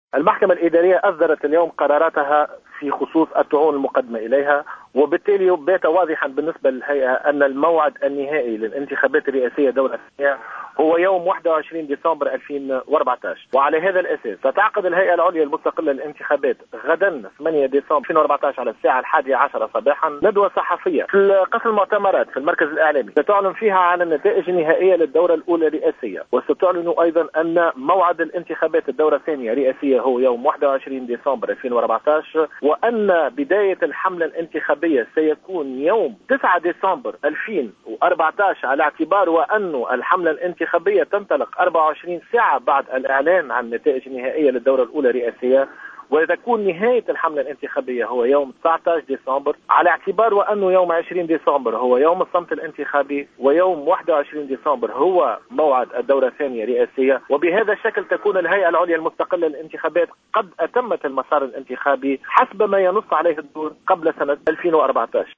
قال نبيل بفون عضو الهيئة العليا المستقلة للانتخابات في تصريح للجوهرة أف أم عقب الإعلان عن الأحكام في الطعون الاستئنافية التي تقدم بها المنصف المرزوقي، أن الموعد الرسمي والنهائي للدور الثاني للانتخابات الرئاسية سيكون يوم الاحد 21 ديسمبر على أن تعقد الهيئة ندوة صحفية للإعلان عن ذلك يوم غد الاثنين.